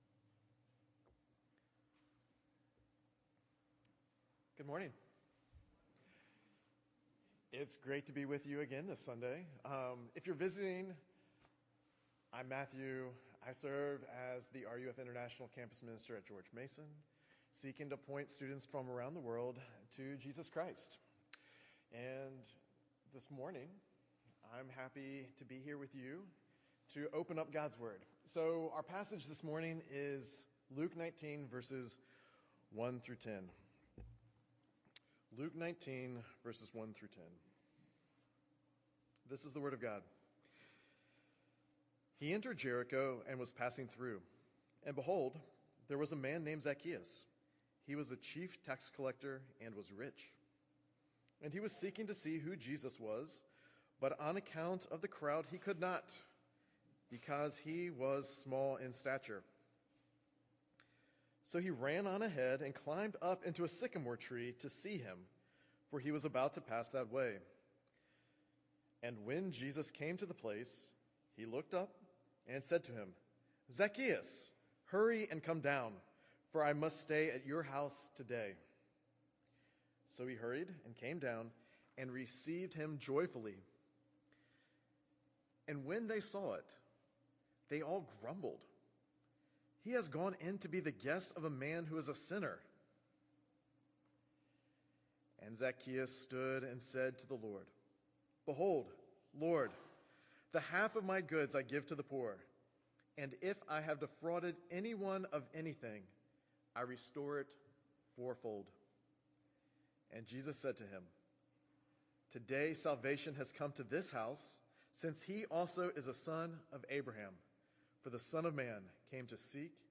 A Wee Little Man: Sermon on Luke 19:1-10 - New Hope Presbyterian Church